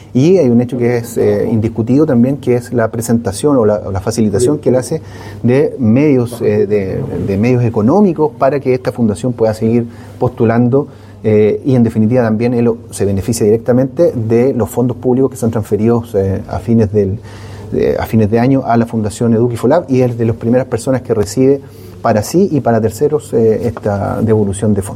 Es en ese contexto, en medio de un receso, que el fiscal Roberto Garrido detalló que en este caso “hay una defraudación que supera los 700 millones de pesos, donde hay varios funcionarios públicos y particulares involucrados”.